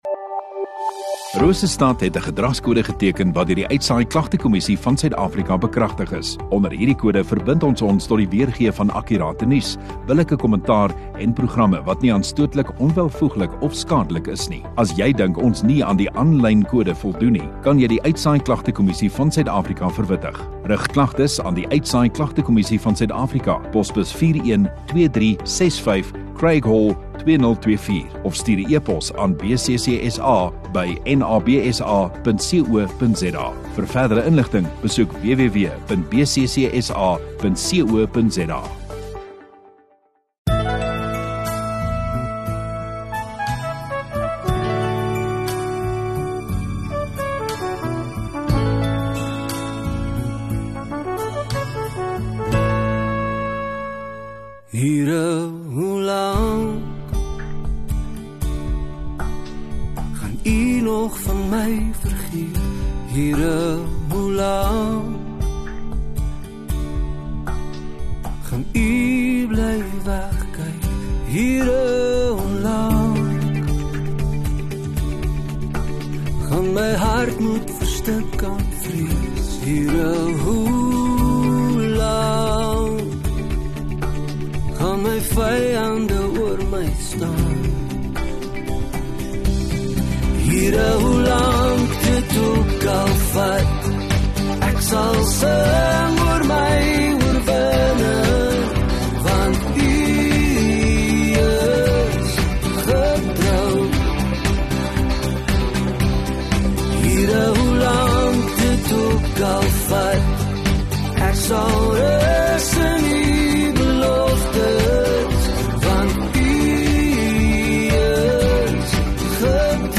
21 Sep Saterdag Oggenddiens